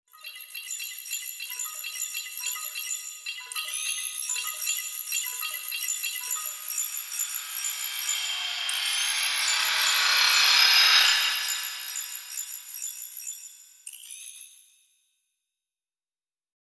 جلوه های صوتی
دانلود صدای ربات 49 از ساعد نیوز با لینک مستقیم و کیفیت بالا